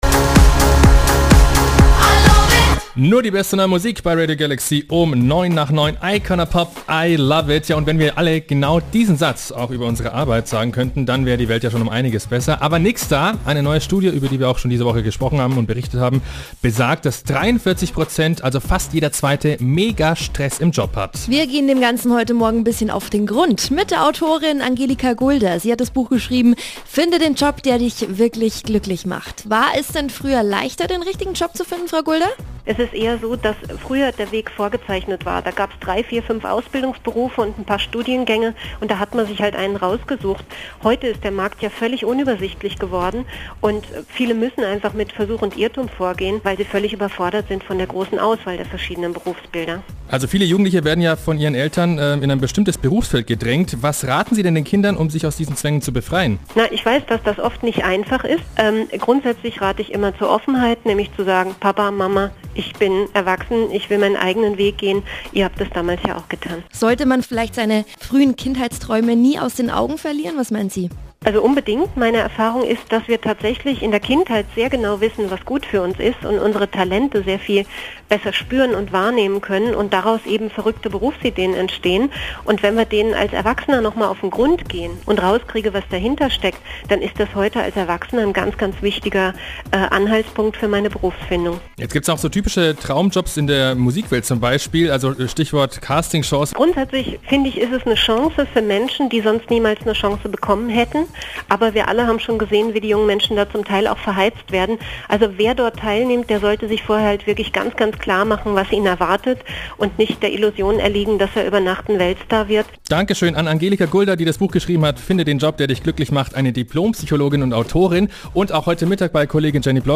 Ein Interview zum Thema Traumjob